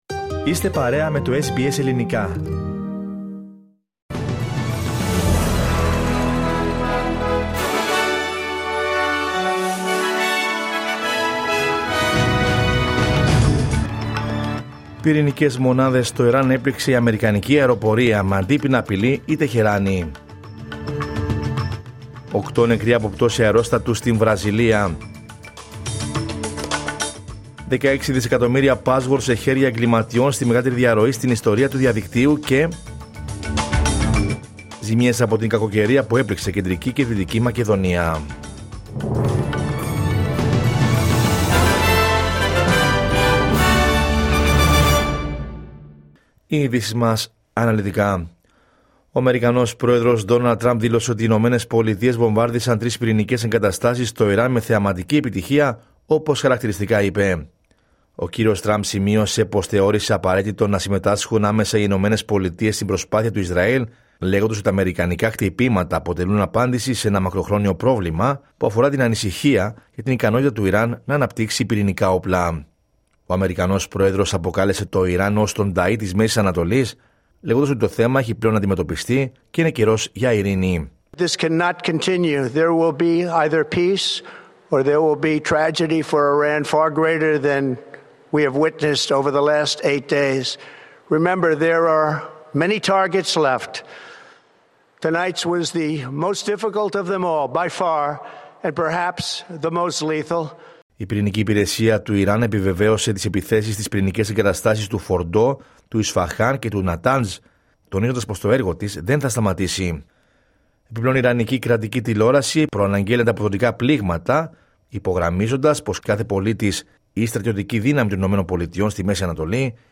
Δελτίο Ειδήσεων Κυριακή 22 Ιουνίου 2025